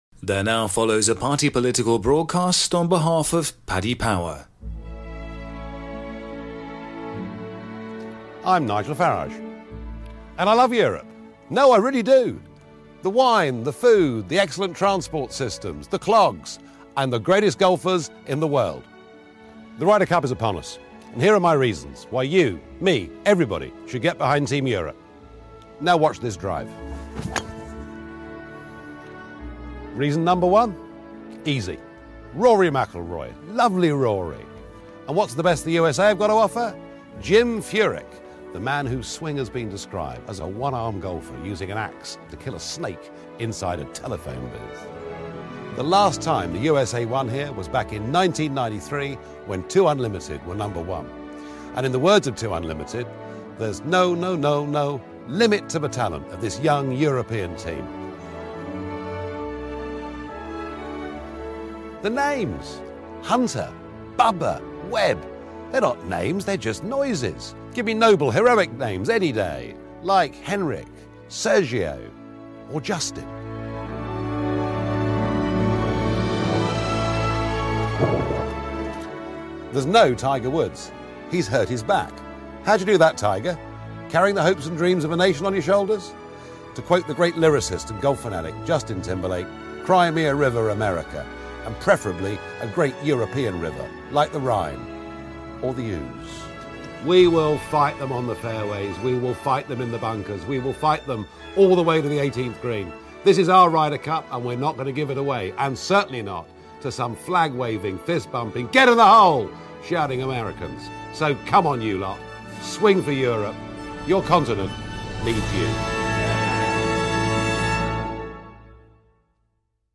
Legendary Eurosceptic Nigel Farage does an incredible reverse-ferret and comes out in support of Team Europe for the Ryder Cup in this mock party political broadcast for Paddy Power.